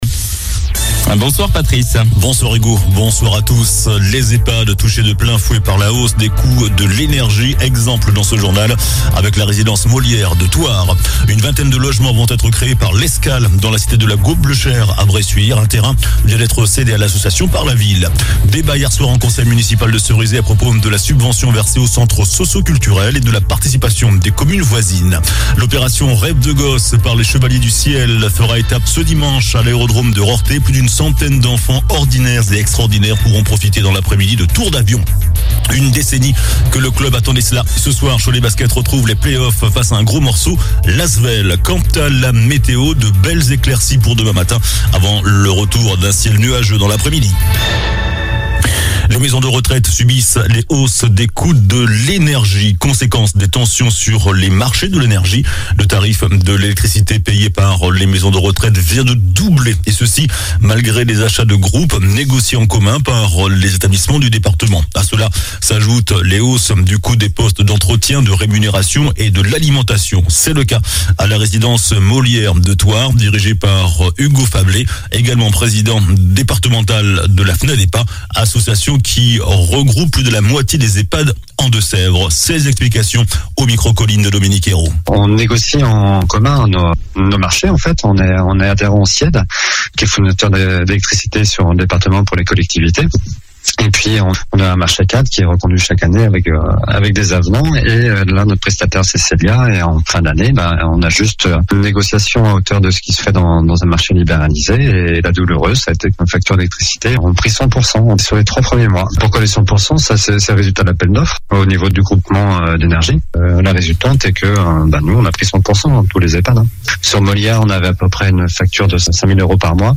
JOURNAL DU MARDI 24 MAI ( SOIR )